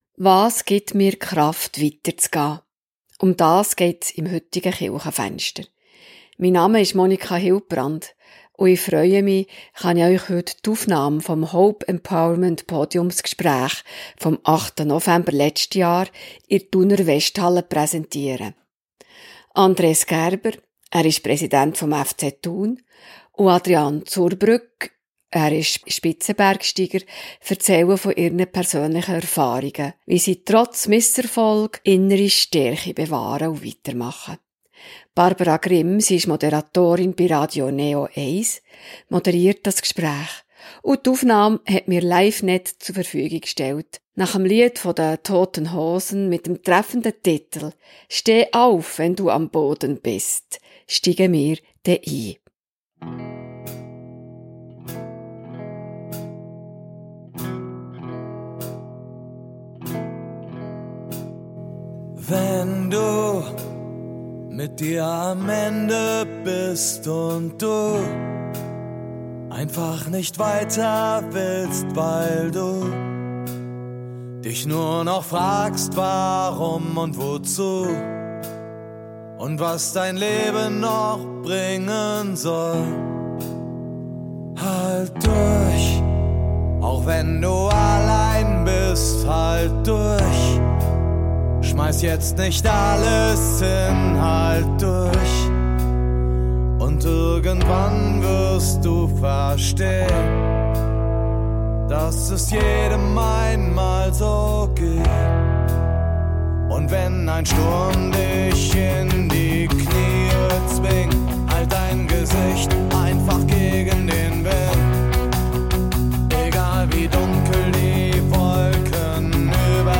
Rund 70 Gäste waren am Hope-Empowerment Podiumsgespräch dabei und erhielten wertvolle Impulse und Inspiration für das eigene Leben. Die beiden Gesprächspartner teilten ihre persönlichen Erfahrungen, wie sie trotz Rückschlägen innere Stärke bewahren und weitermachen – sei es nach einer schwierigen Fussball-Saison oder mitten in einem herausfordernden Bergabenteuer.